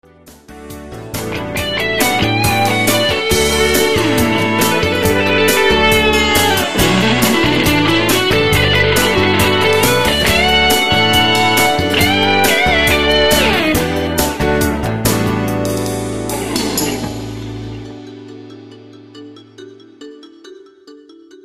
Hier im semiprof - Studio entstehen z.B. Demo-Produktionen, Rundfunkspots, Jingles, Halbplaybacks, Theatermusik... - einige Beispiel-Ausschnitte gibt's als MP3_files
Neben 'richtigen' Instrumenten gibt's diverse MIDI-Klangerzeuger, NEUMANN TLM 170 Micros, TLA Compressor, YAMAHA O2R-Mixer u.s.w.
Bsp Chorus Nie wieder